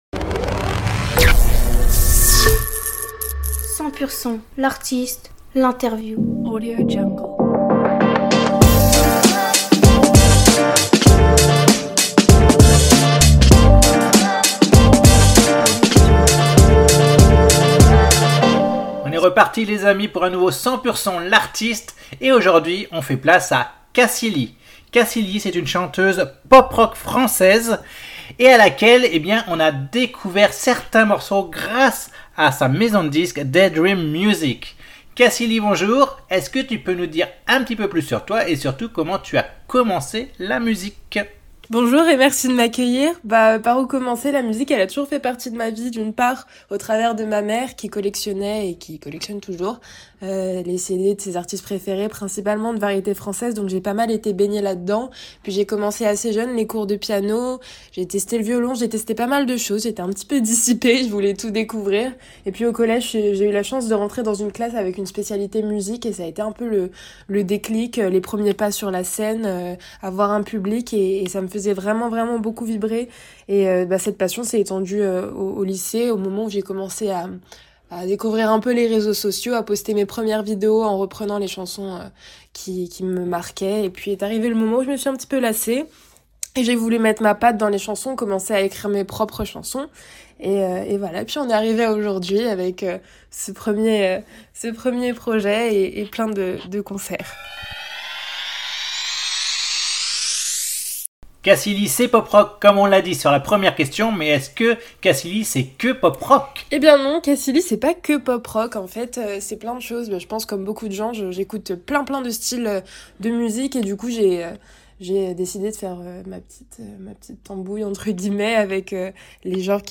Interview Artiste